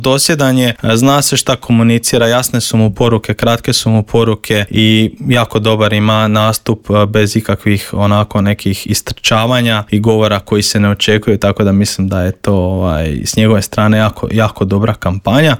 ZAGREB - Utrka za Pantovčak u punom je zamahu.